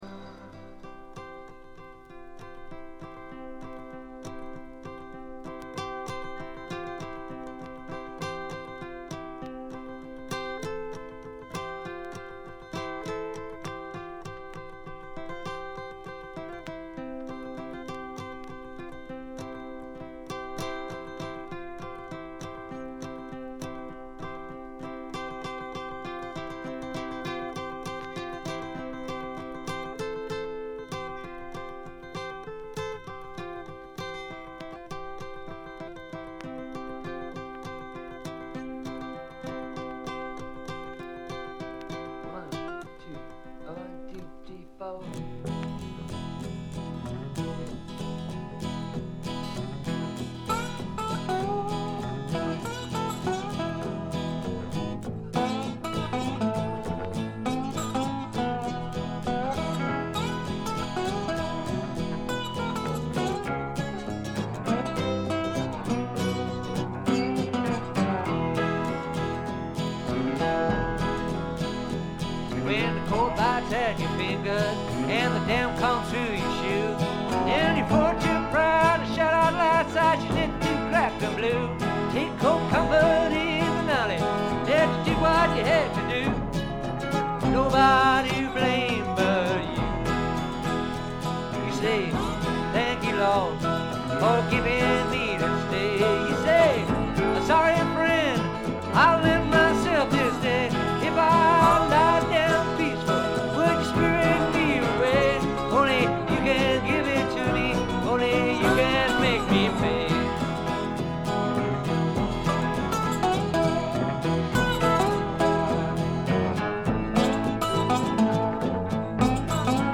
A3イントロで軽微なチリプチ。
試聴曲は現品からの取り込み音源です。